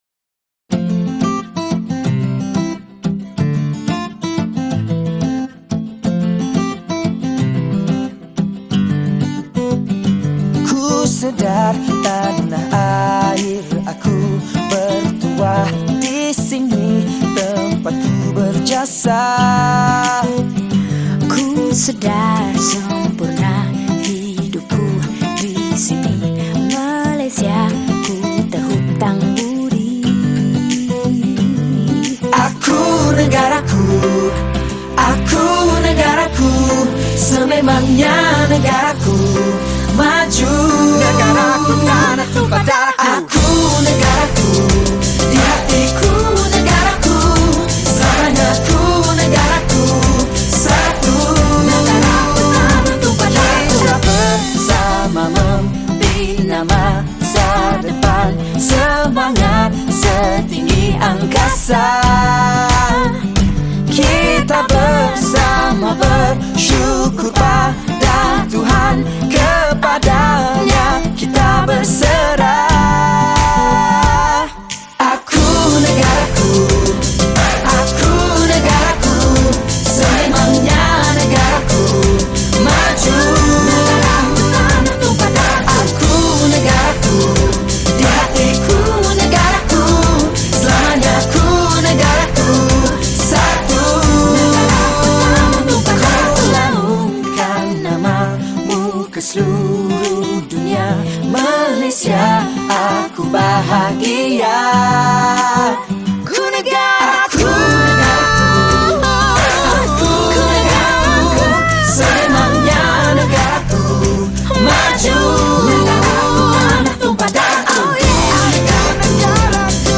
Malay Songs , Patriotic Songs